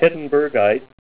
Say HEDENBERGITE Help on Synonym: Synonym: ICSD 10226   PDF 41-1372